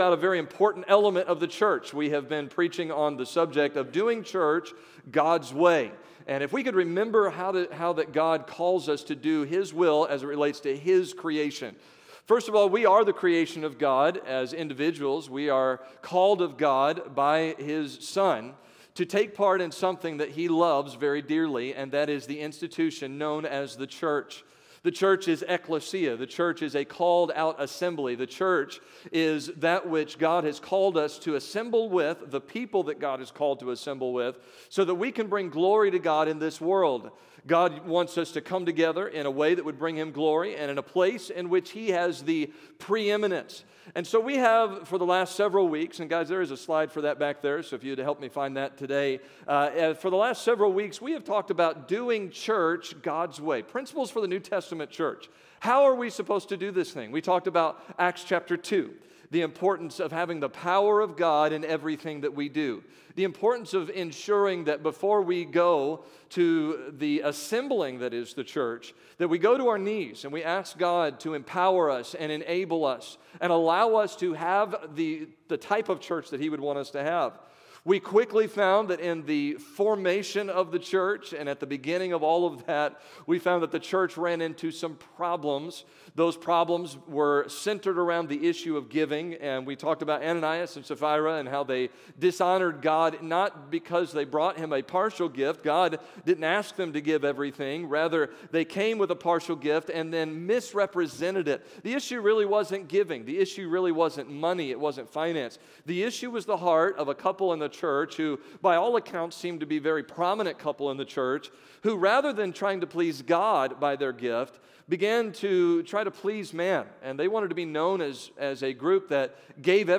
September 2022 Sunday Morning Scripture: 1 Corinthians 1,2 Download: Audio